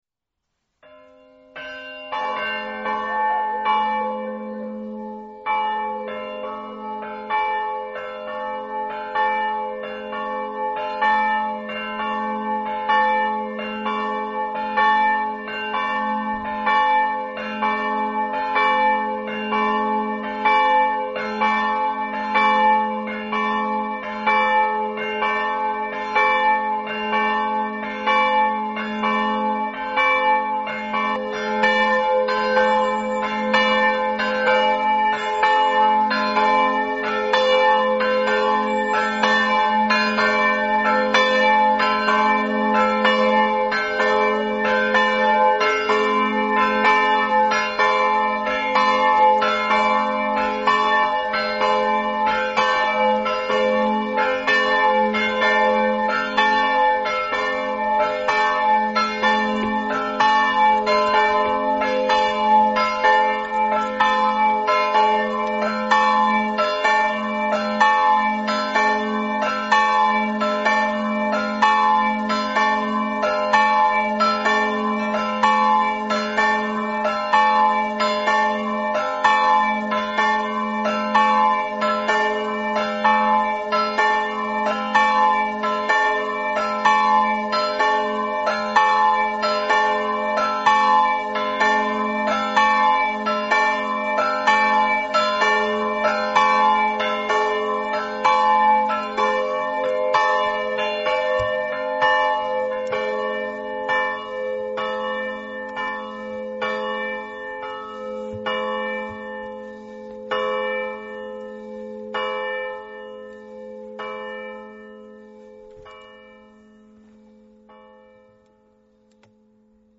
Leproso di Premariacco (UD), 19 Settembre 2024
CAMPANE